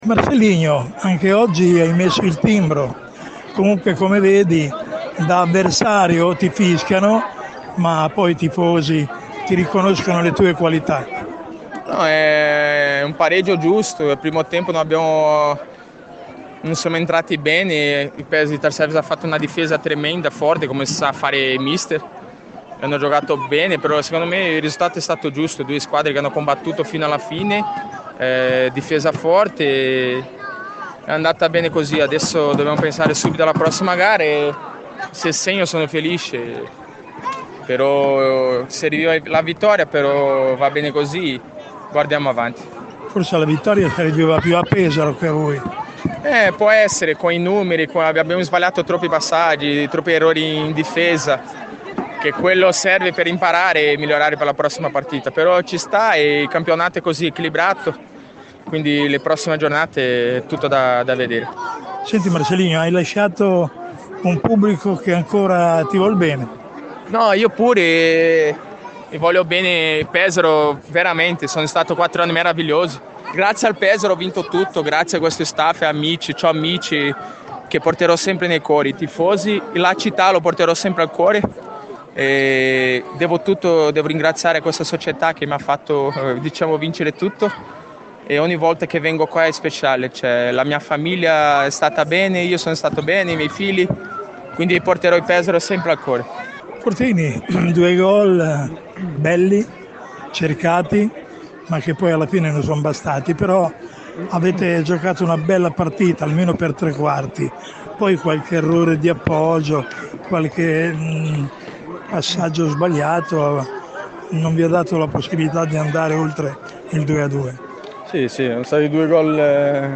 Le interviste post partita